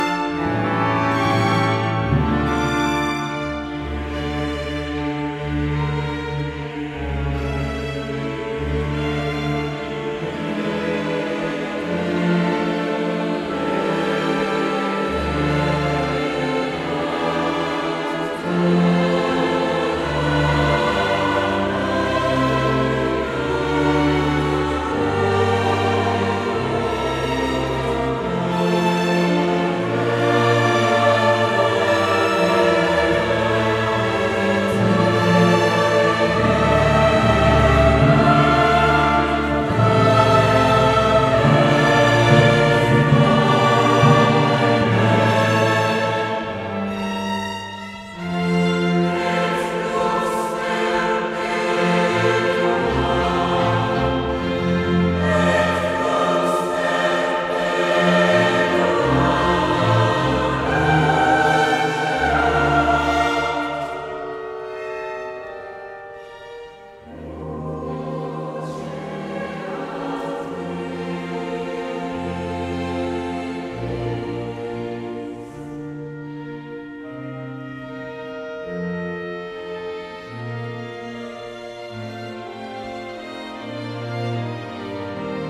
Affiche du concert 2021 du Choeur symphonique de Fribourg
CSF_2021_Requiem_extrait-1.mp3